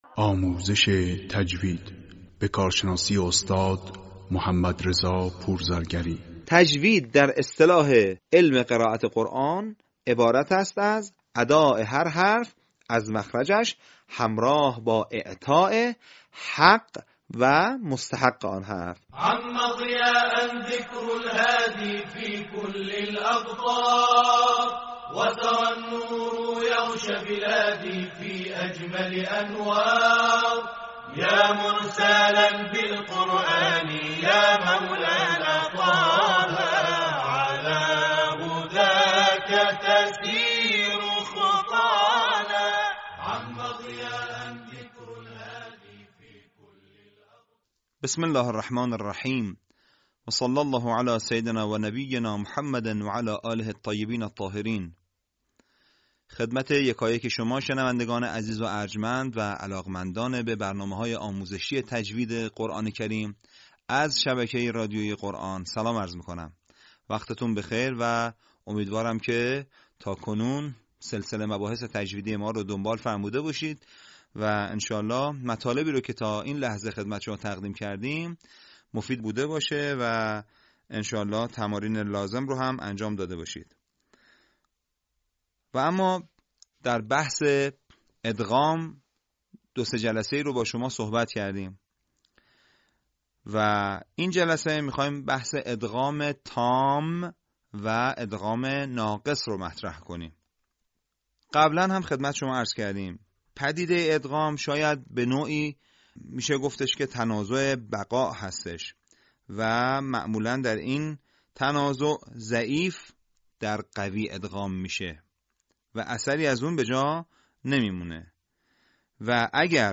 آموزش تجویدی قرآن کریم قسمت شصت و چهارم